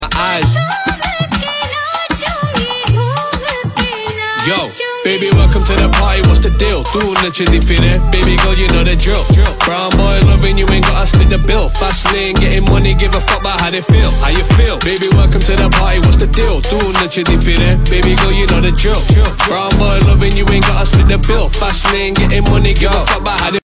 Catchy Vibes Everywhere